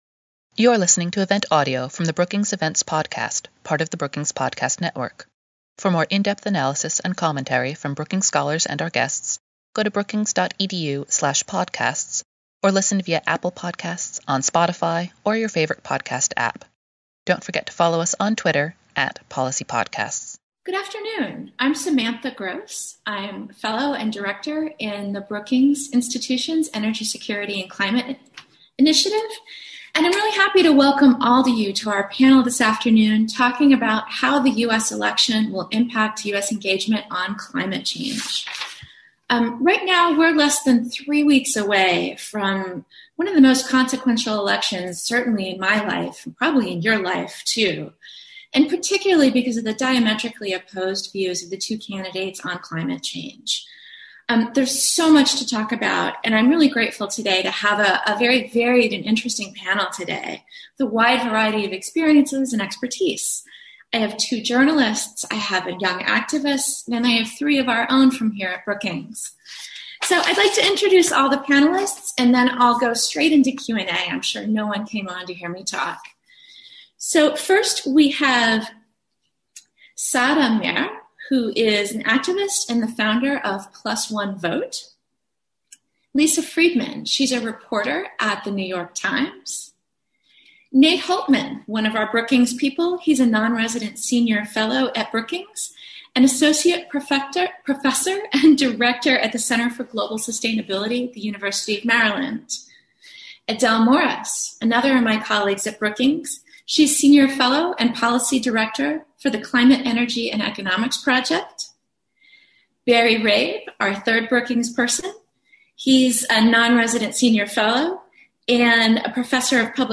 On October 15, Brookings will host a discussion about what the 2020 election outcome will likely mean for U.S. engagement on climate change.